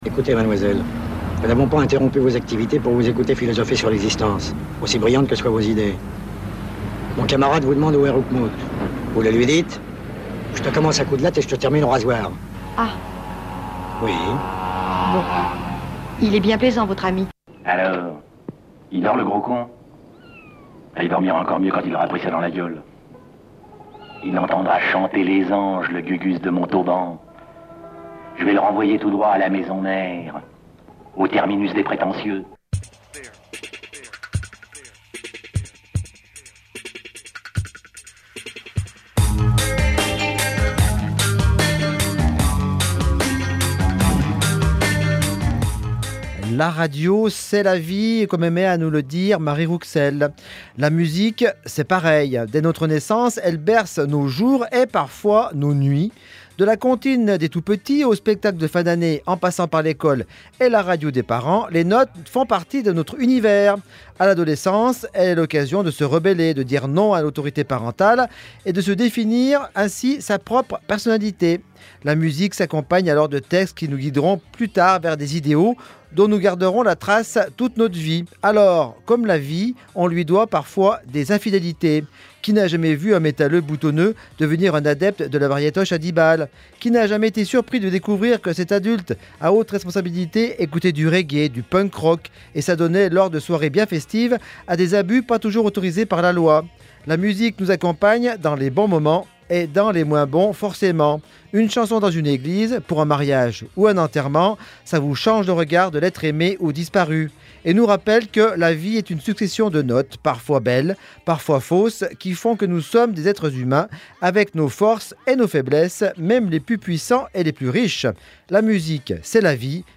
Radio B, la radio locale de Bourg-en-Bresse et des Pays de l'Ain